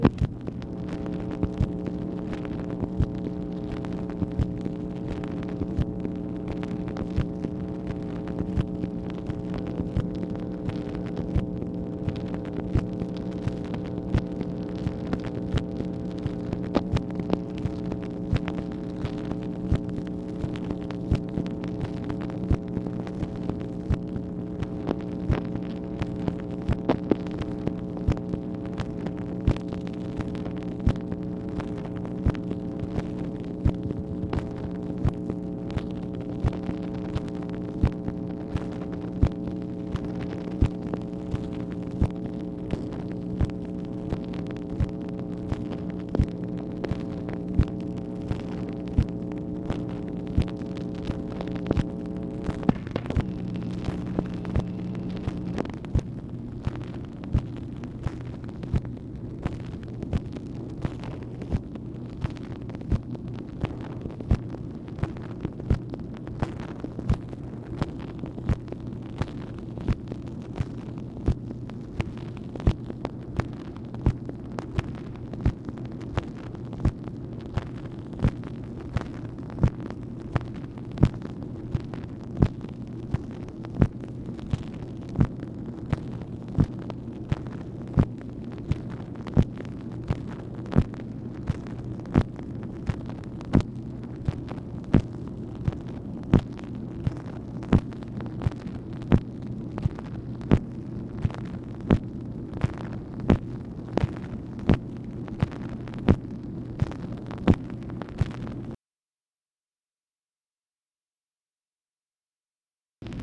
MACHINE NOISE, August 4, 1964
Secret White House Tapes | Lyndon B. Johnson Presidency